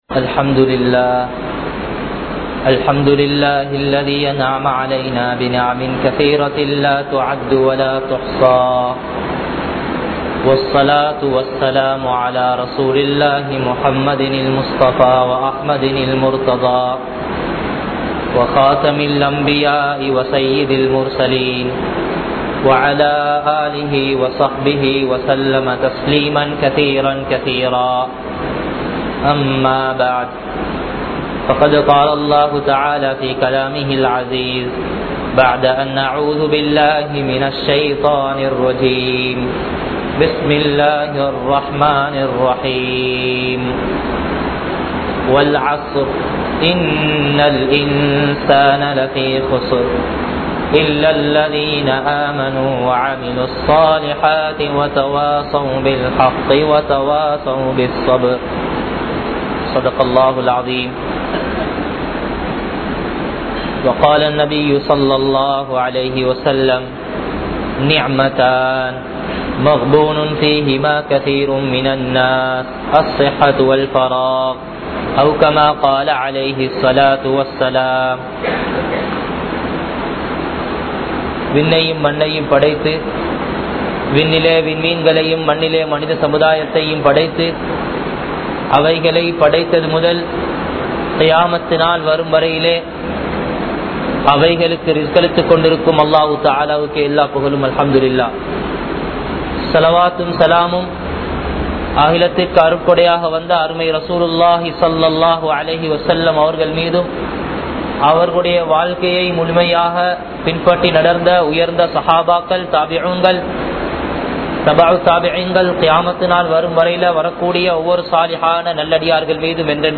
Islam Ethirpaarkum Vaalifarhal (இஸ்லாம் எதிர்பார்க்கும் வாலிபர்கள்) | Audio Bayans | All Ceylon Muslim Youth Community | Addalaichenai